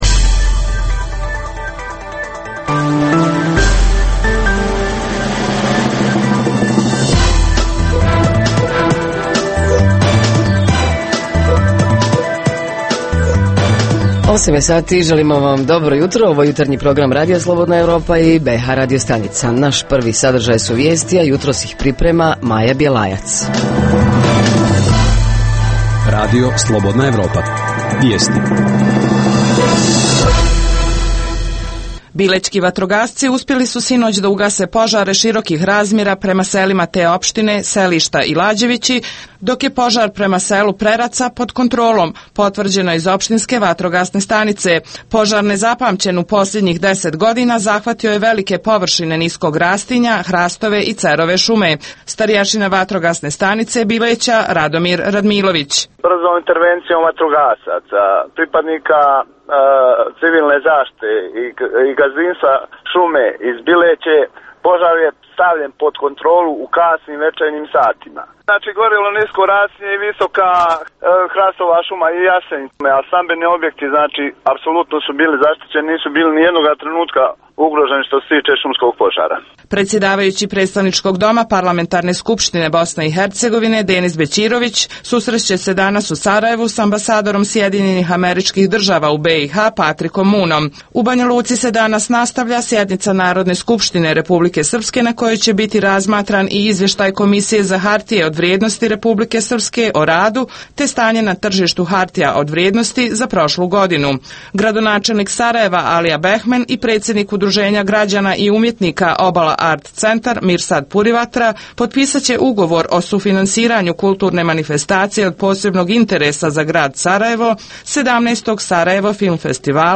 Jutarnji program za BiH koji se emituje uživo. Sadrži informacije, teme i analize o dešavanjima u BiH i regionu. Reporteri iz cijele BiH javljaju o najaktuelnijim događajima u njihovim sredinama.